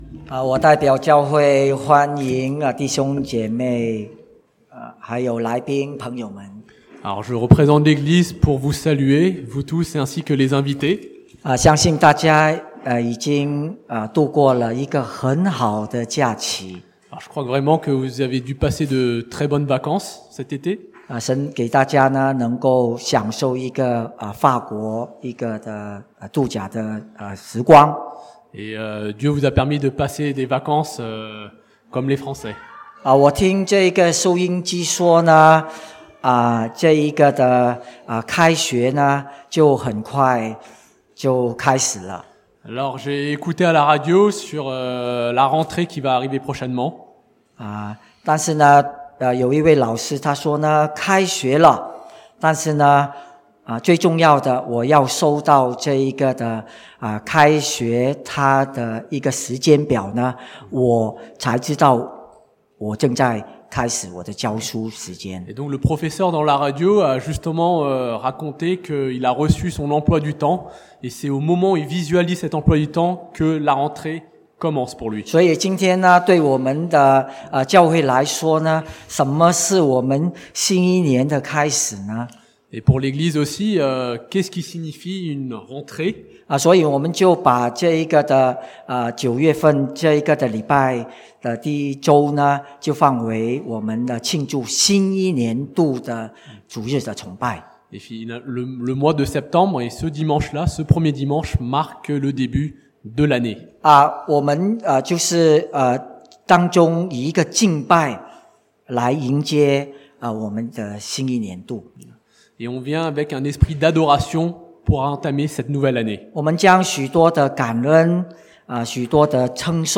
(chinois traduit en français)